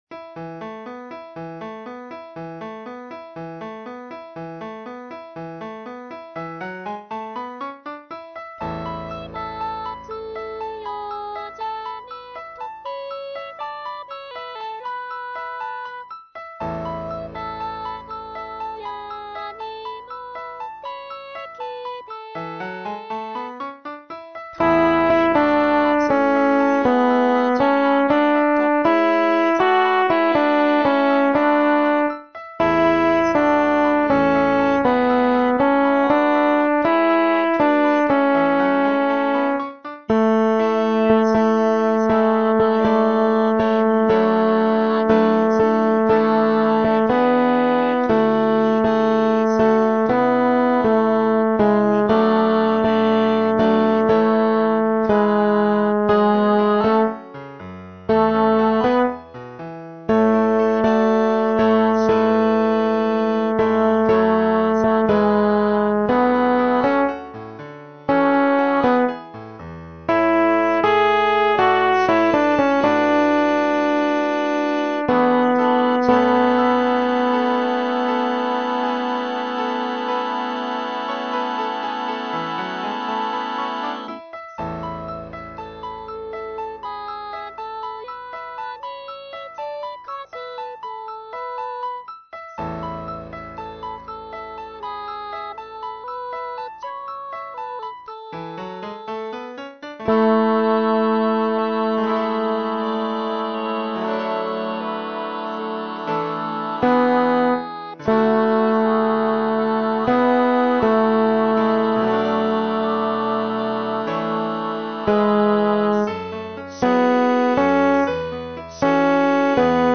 テノール1（フレットレスバス音）